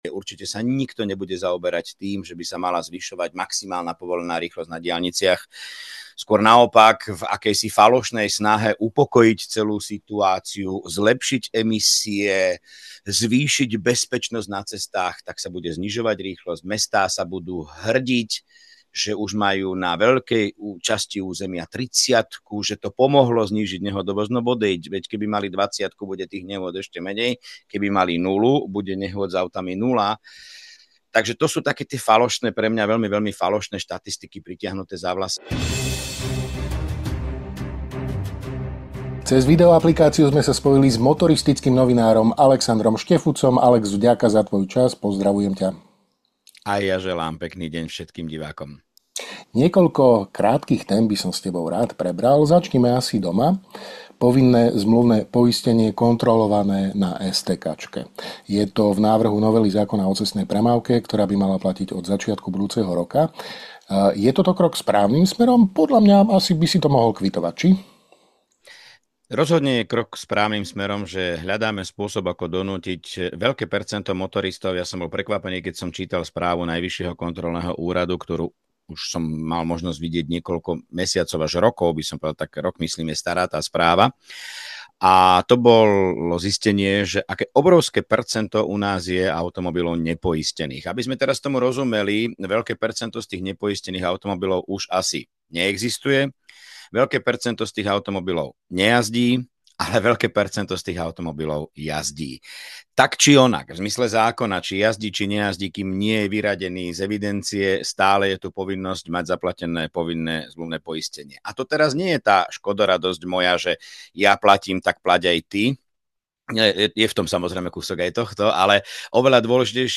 Viac vo videorozhovore.